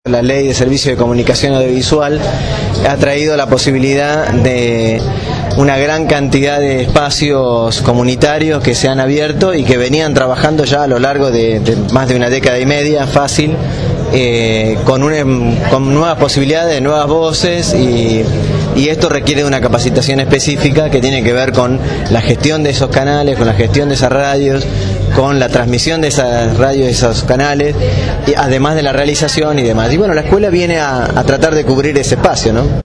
Con la presencia del Ministro de Trabajo Carlos Tomada quedó inaugurada la Escuela Popular de Medios Comunitarios.